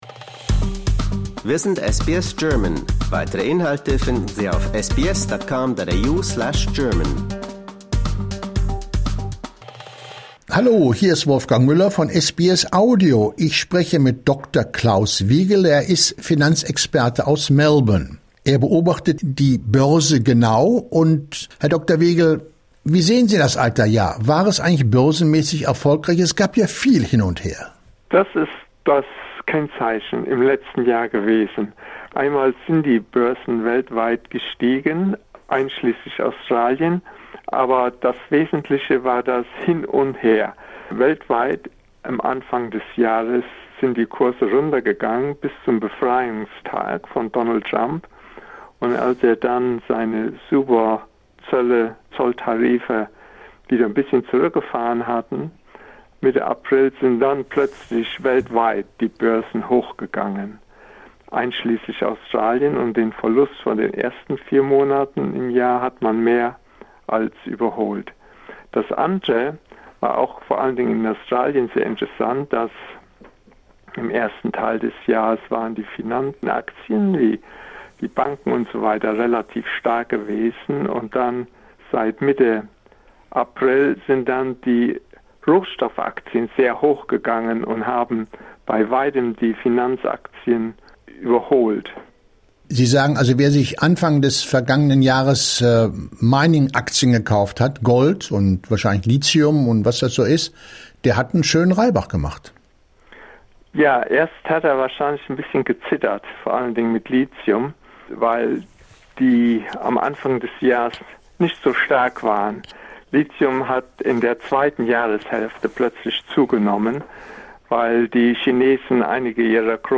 Disclaimer: Wir möchten Sie darauf hinweisen, dass die in diesem Beitrag geäußerten Meinungen die persönlichen Ansichten der interviewten Person/des Gesprächspartners darstellen.